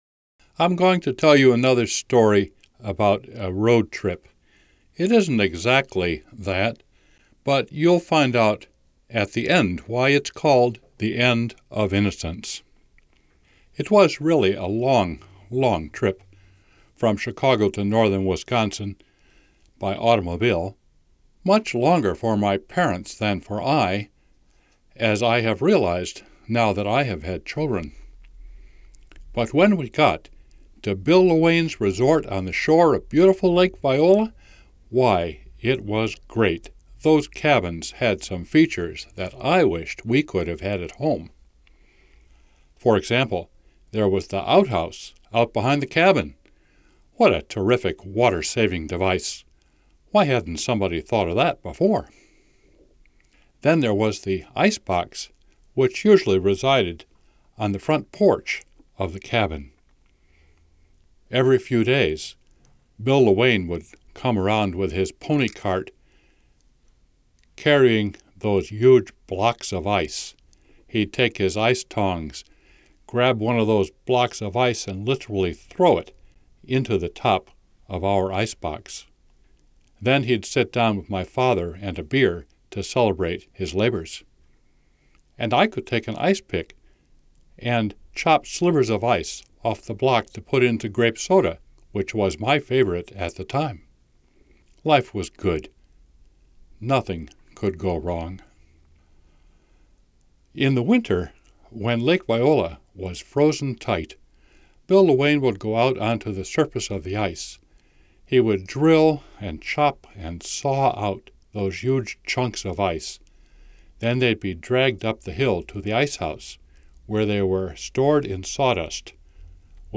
Story Summary: (Personal Narrative: 9 minutes) The storyteller looks back on a childhood vacation experience with the knowledge that the fun must end at some time.